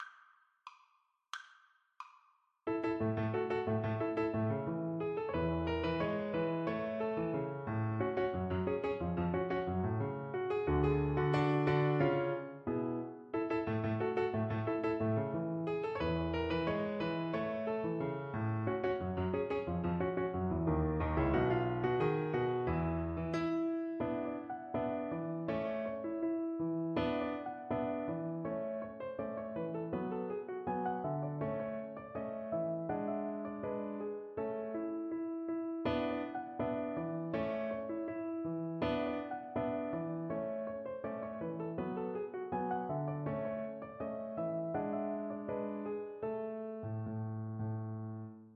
2/4 (View more 2/4 Music)
Allegro con brio ( = c. 90) (View more music marked Allegro)
Classical (View more Classical Violin Music)
Cuban